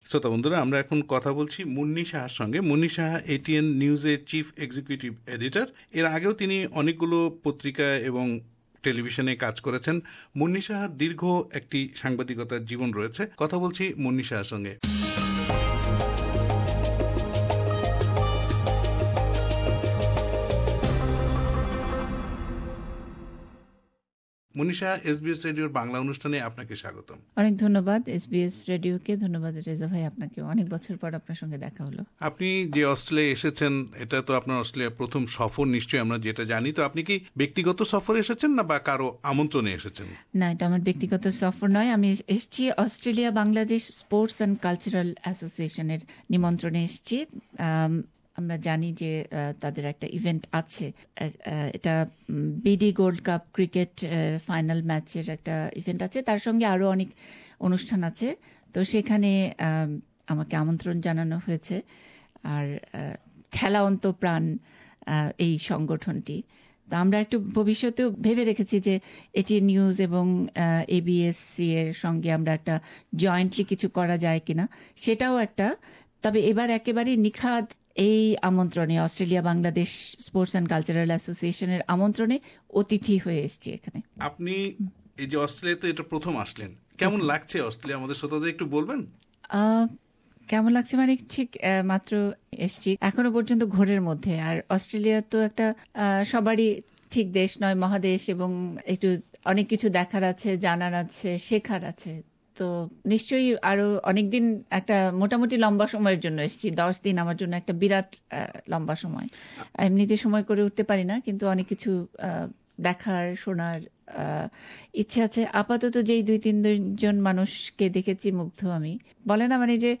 Interview with Munni Saha